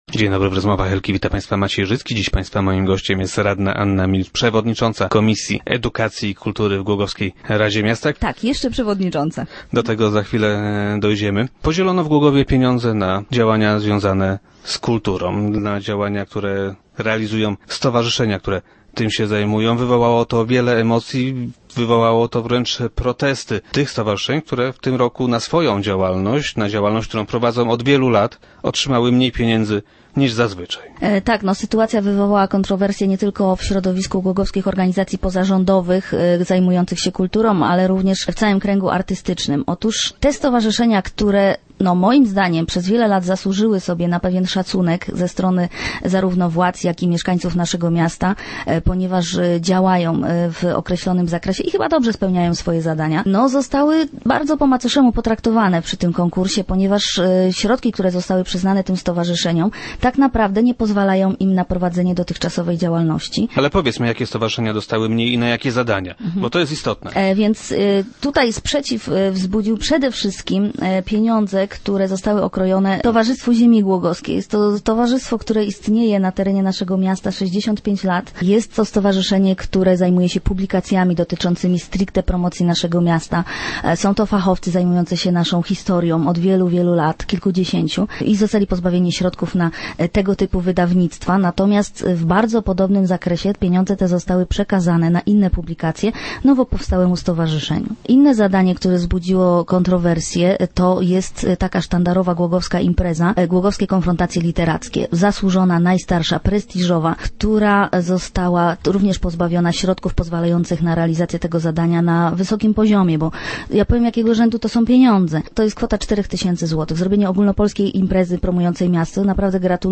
0314_milicz.jpgPodział gminnych pieniędzy pomiędzy stowarzyszenia kulturalne wywołał wiele emocji i kontrowersji. Z obcięciem dotacji dla stowarzyszeń od lat działających w Głogowie nie zgadza się również radna Anna Milicz, która była dziś gościem Rozmów Elki.